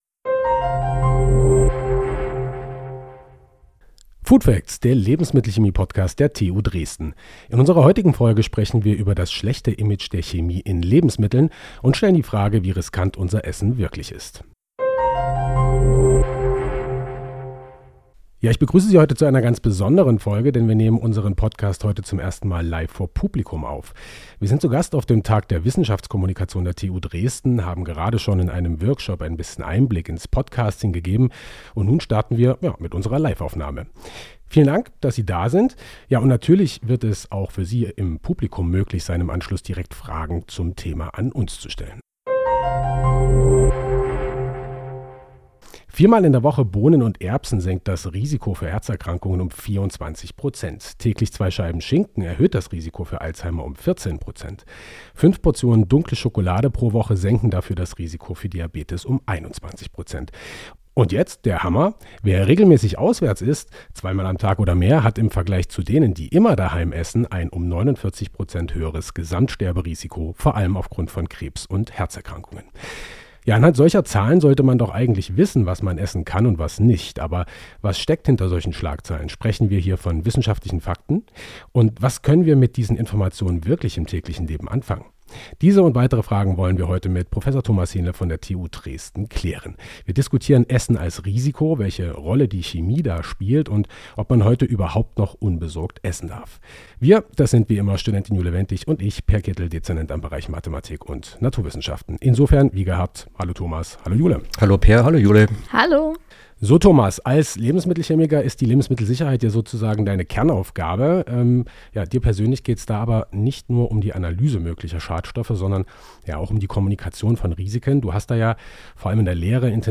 Sie klären de Begriffe „Gefahr“ und „Risiko“, reden über potenzielle Giftstoffe in Lebensmitteln und besprechen wo man beim Essen möglicherweise tatsächlich Risiken eingeht. Dazu gibt es einige Tipps für den Alltag, zum Beispiel im Hinblick auf die startende Grillsaison. Besonderes Highlight: Die Folge wurde anlässlich des Tages der Wissenschaftskommunikation an der TU Dresden live vor Publikum aufgenommen.